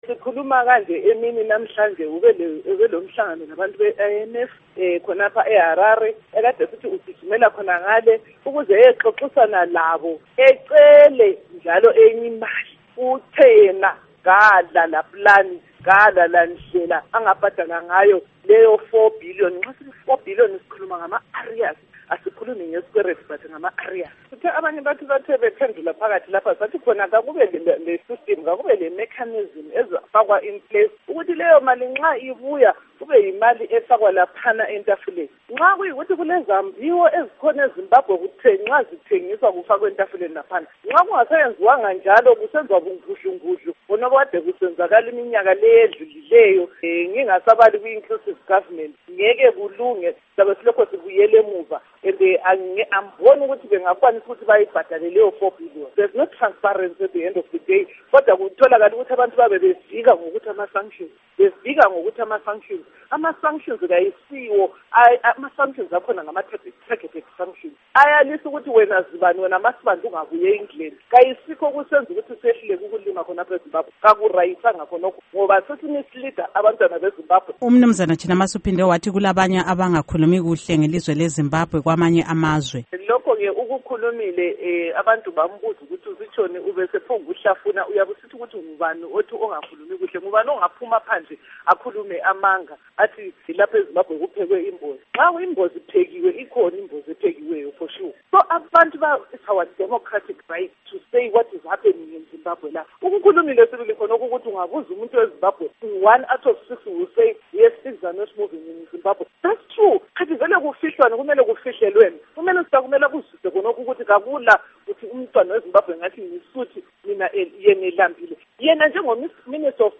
Ingxoxo LoNkosikazi Dorcas Sibanda